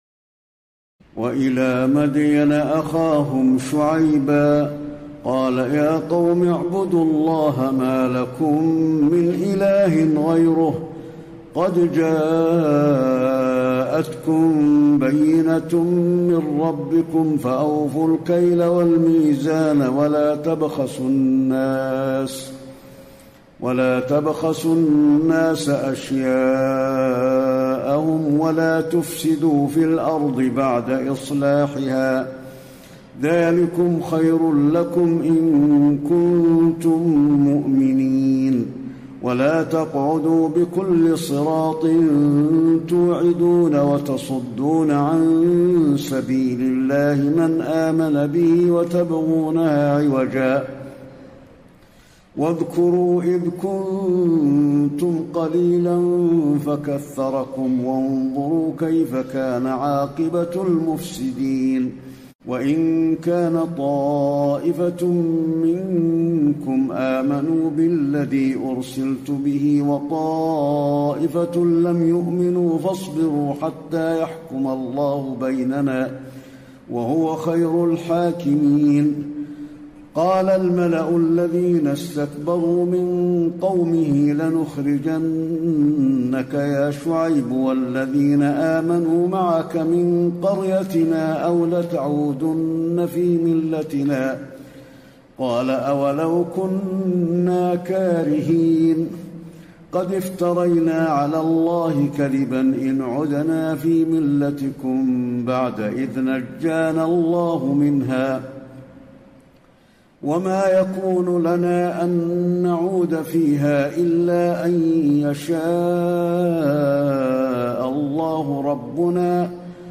تراويح الليلة التاسعة رمضان 1434هـ من سورة الأعراف (85-170) Taraweeh 9 st night Ramadan 1434H from Surah Al-A’raf > تراويح الحرم النبوي عام 1434 🕌 > التراويح - تلاوات الحرمين